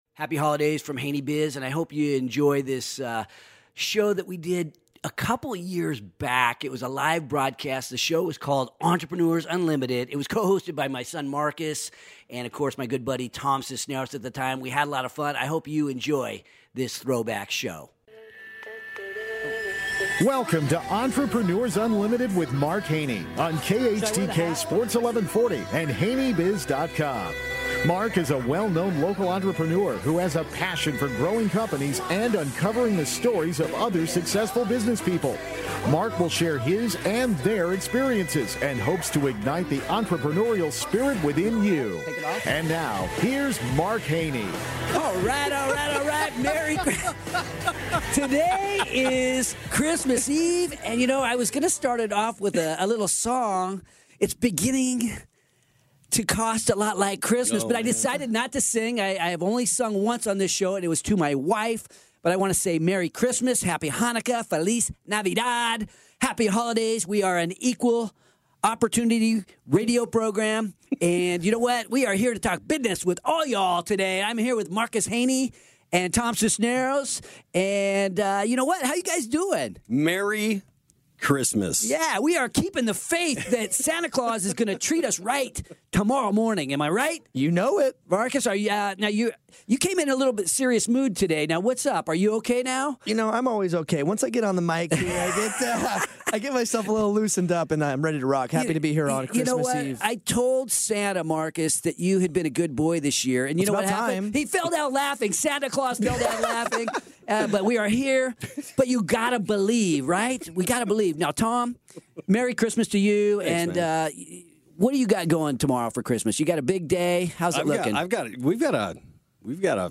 This is a special throwback show to when the show was Entrepreneurs Unlimited and it was a live radio talk show.